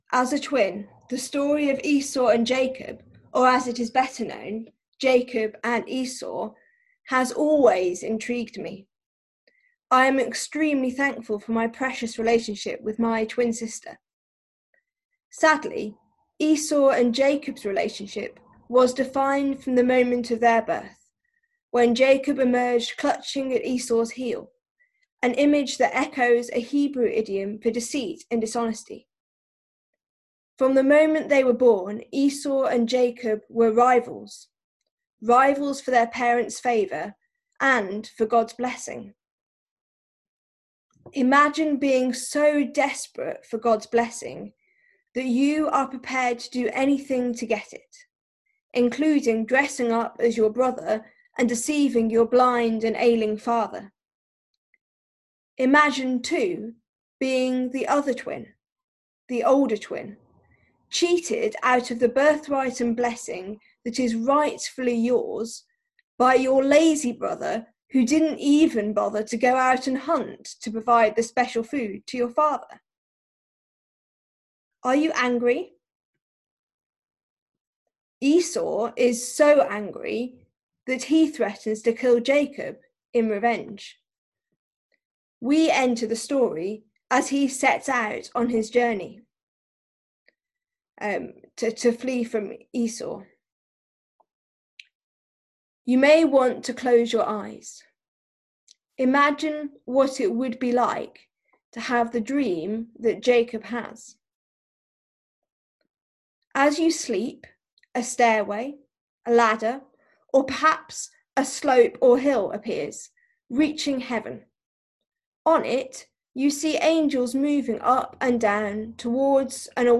This sermon was first shared for St Denys Evington zoom service on 9th May 2021.